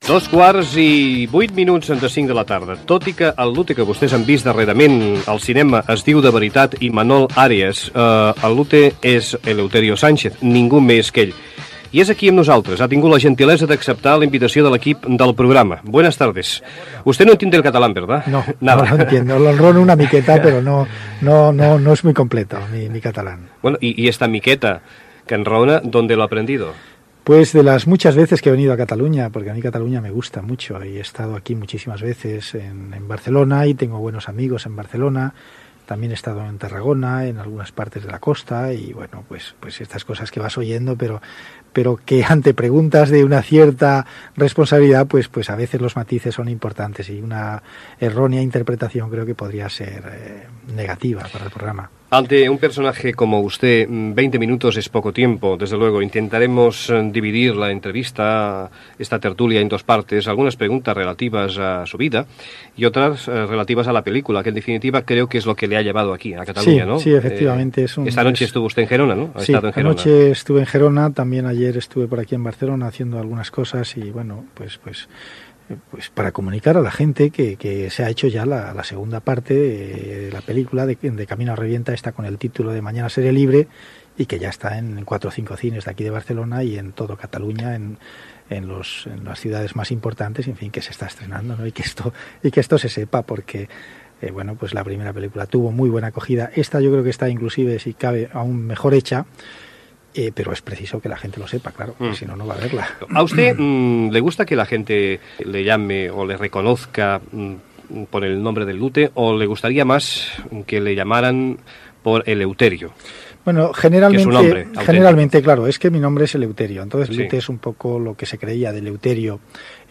Fragment inicial de l'entrevista a Eleuterio Sánchez "El Lute" amb motiu de la segona pel·lícula basada en la seva vida.
Info-entreteniment